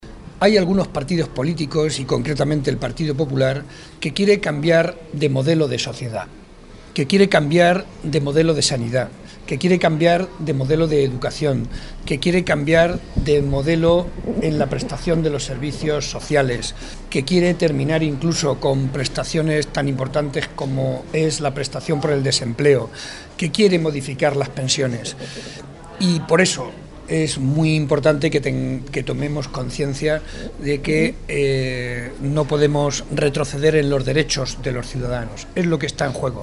Mantener un sistema público o abrir las puertas a uno privado. Esto es lo que se juegan los ciudadanos en el próximo domingo, tal y como ha señalado el secretario regional del PSOE y candidato al Congreso de los Diputados por Ciudad Real, José María Barreda, en un acto público celebrado en La Solana ante más de 300 personas junto al secretario provincial, Nemesio de Lara, y el alcalde de La Solana, Luis Díaz-Cacho.
Cortes de audio de la rueda de prensa